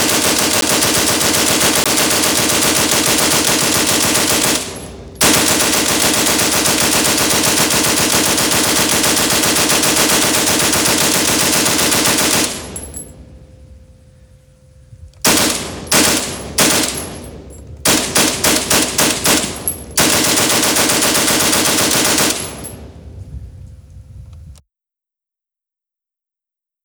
Vickers+Machine+Gun+sound.wav